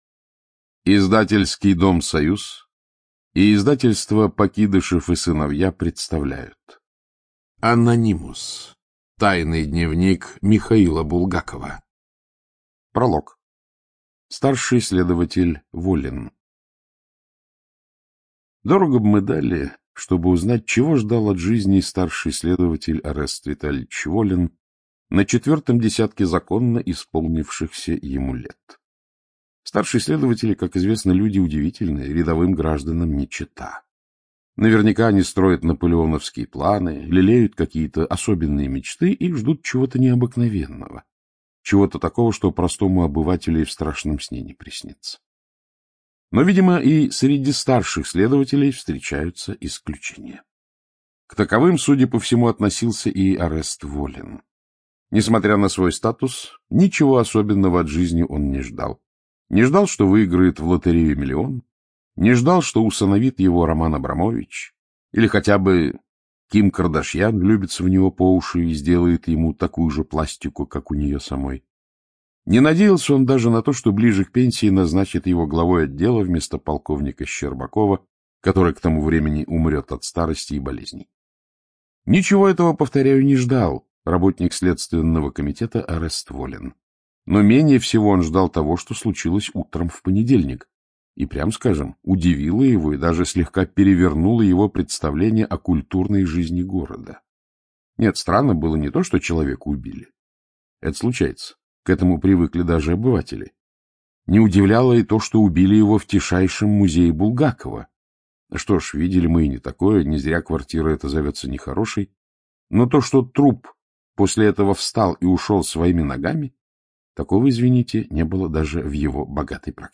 ЧитаетКлюквин А.
Студия звукозаписиСоюз